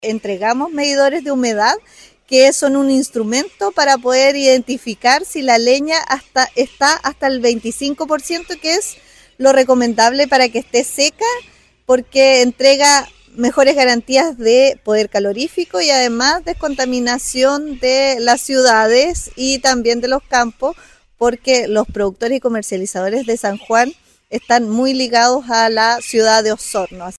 Ante ello, la seremi explicó que San Juan de la Costa, particularmente, es un importante sector productivo, asociado al consumo de leña en la ciudad de Osorno.